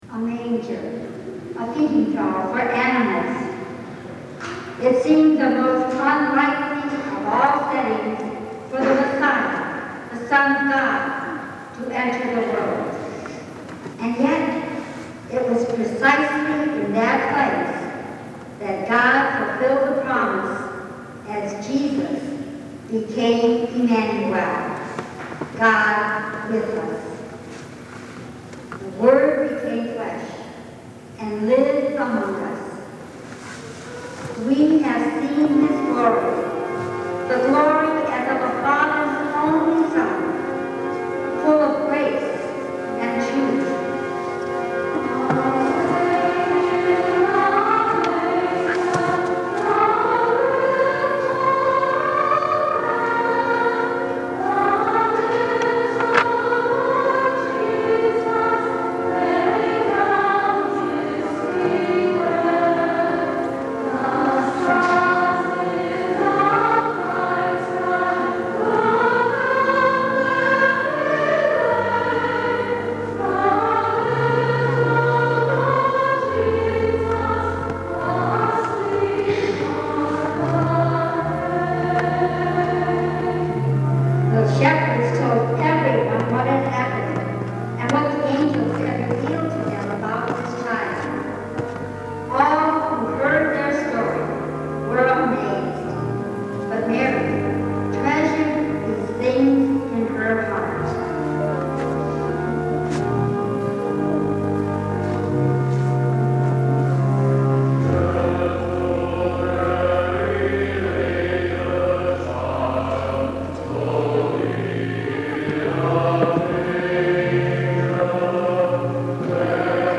Saint Clement Choir Sang this Song
Christmas-Concert